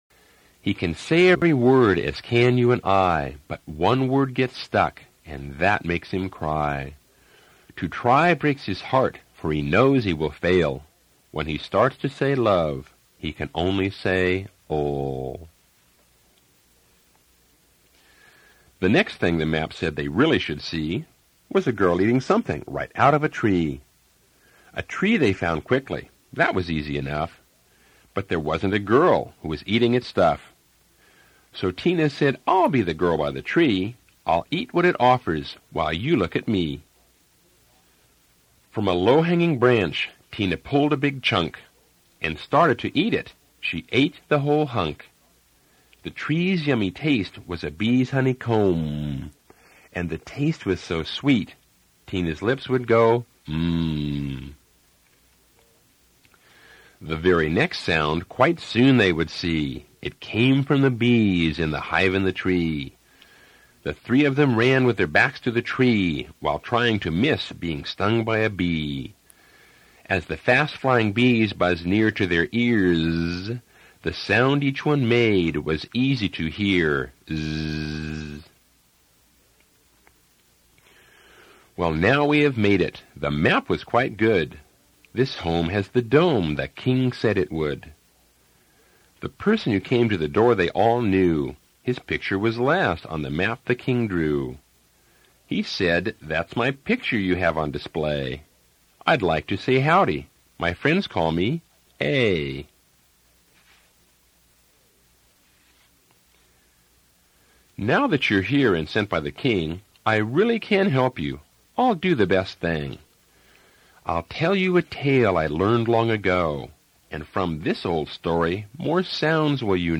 Reading of Dekodiphukan